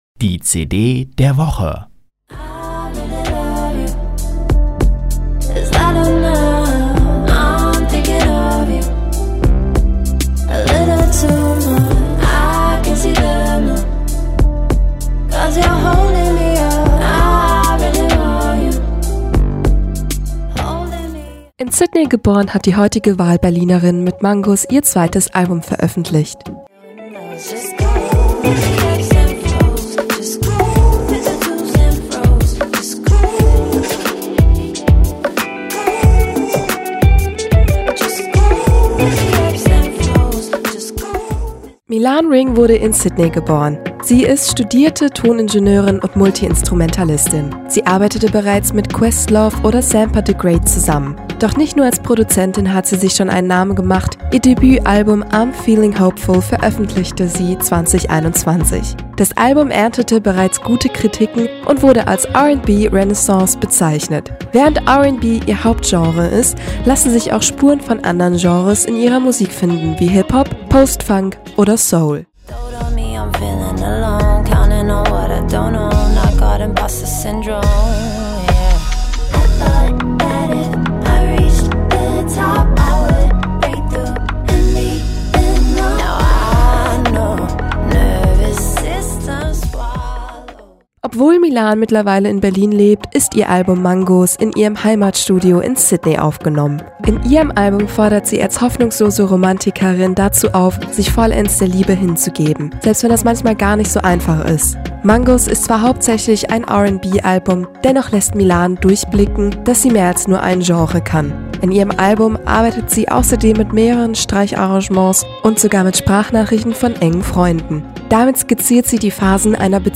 Sie ist studierte Toningenieurin und Multiinstrumentalistin.
hauptsächlich ein R&B-Album